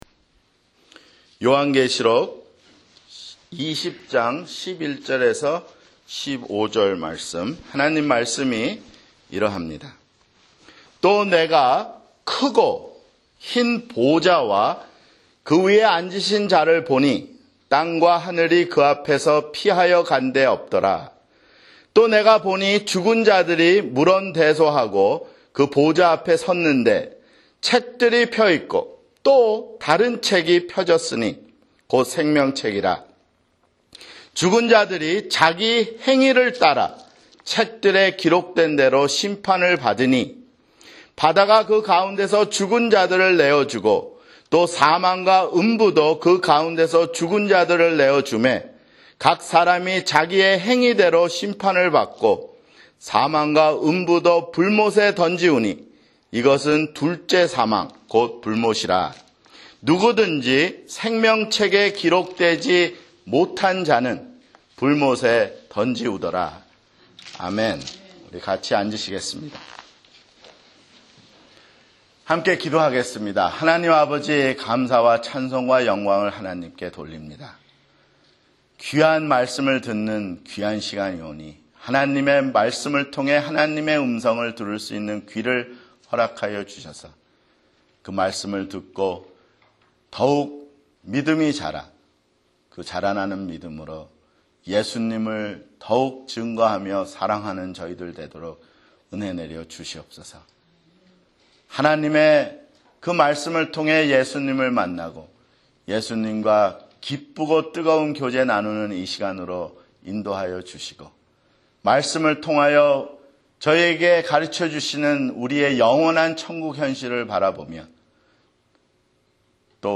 [주일설교] 요한계시록 (80)